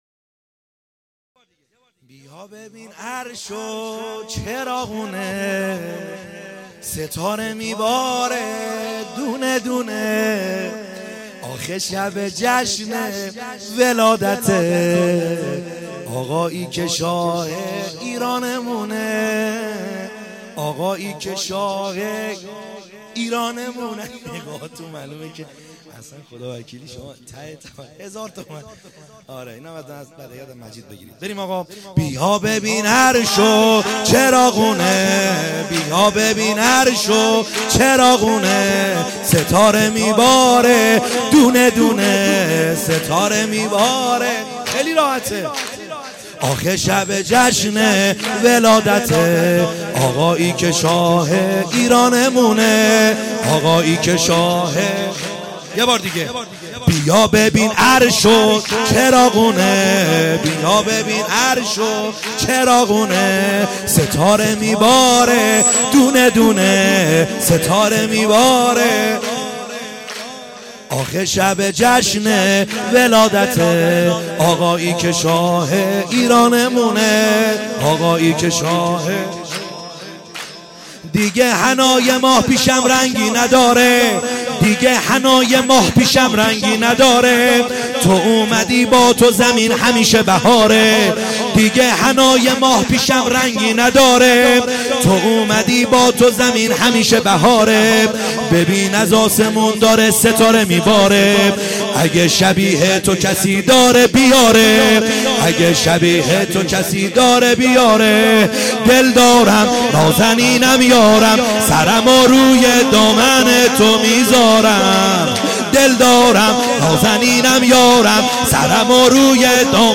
خیمه گاه - بیرق معظم محبین حضرت صاحب الزمان(عج) - سرود | بیا ببین عرض چراغونه